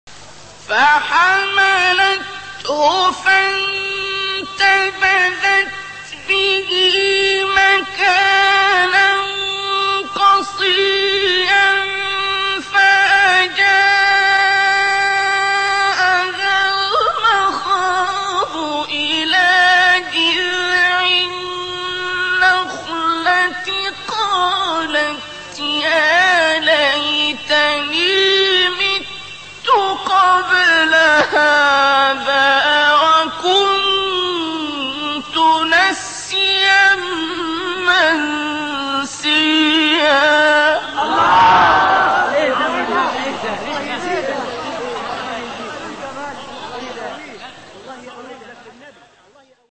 گروه فعالیت‌های قرآنی: مقاطع صوتی با صدای قاریان ممتاز کشور مصر را می‌شنوید.